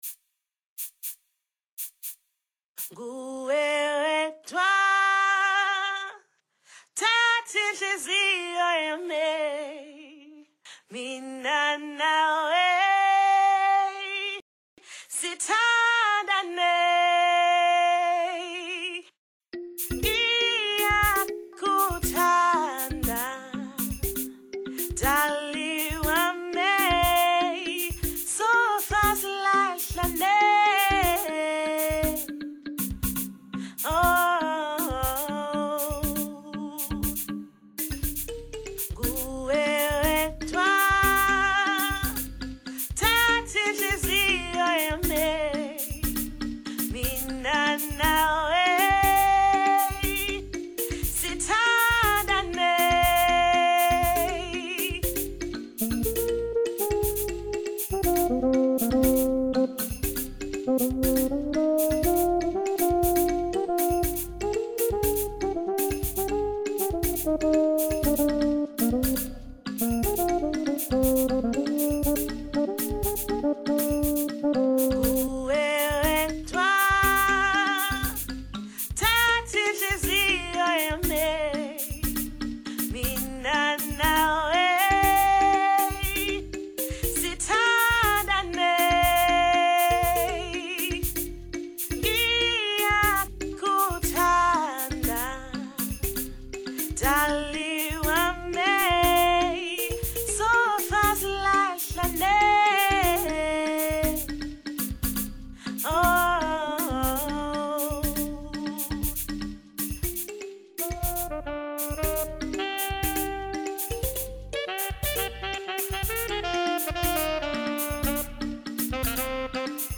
Indigenous ballad by South African vocalist.
African Folk